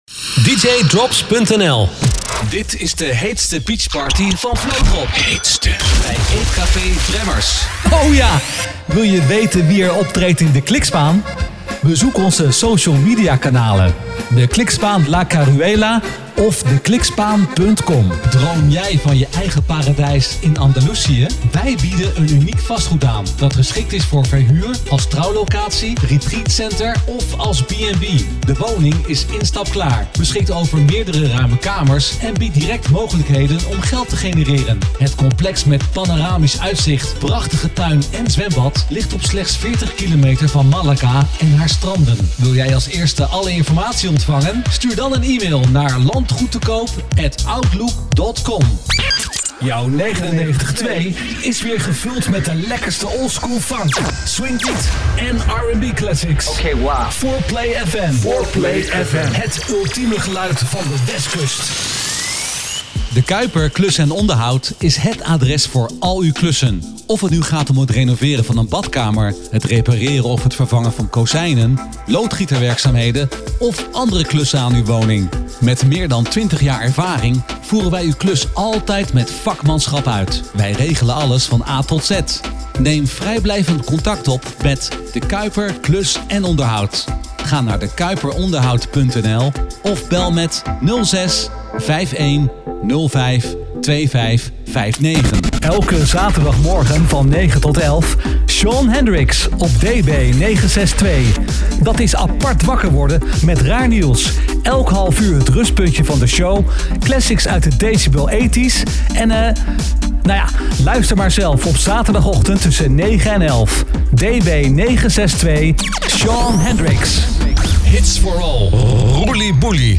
Huisstem